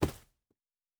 Footstep Carpet Running 1_09.wav